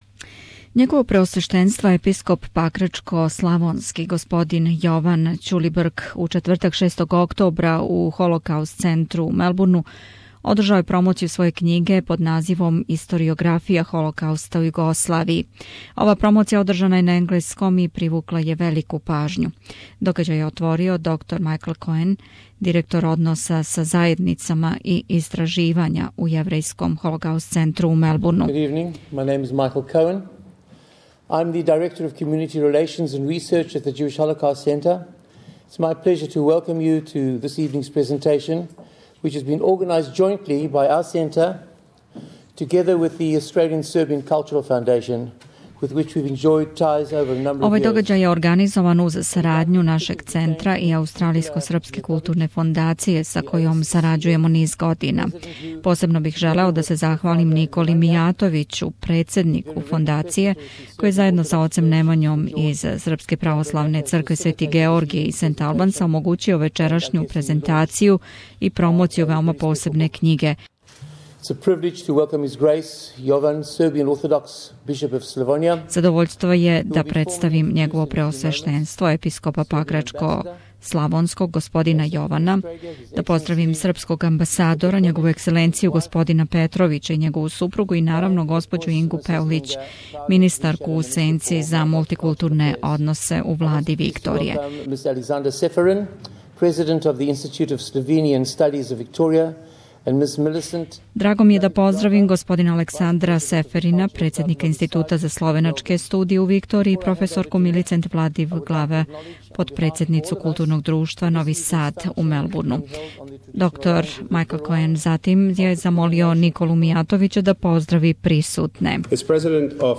Public lecture on Historiography of Holocaust in Yugoslavia by His Grace Bishop Jovan Culibrk Source